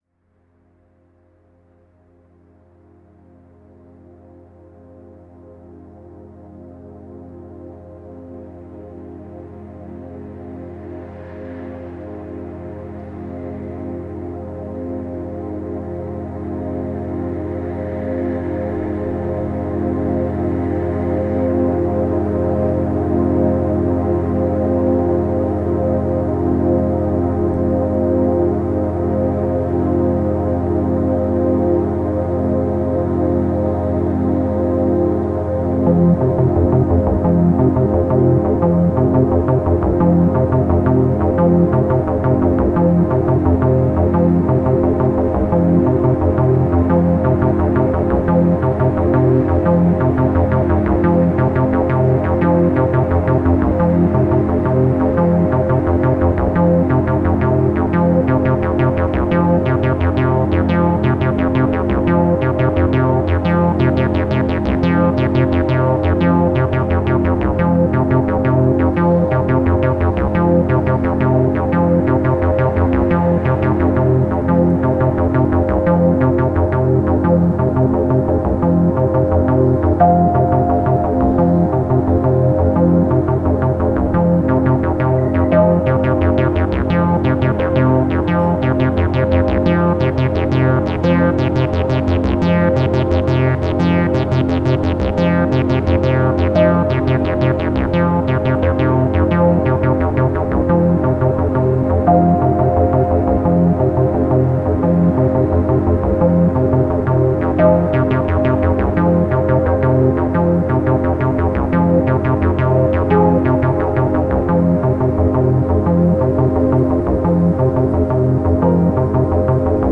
Genre: Ambient/Deep Techno/Dub Techno/Electro.